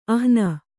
♪ ahna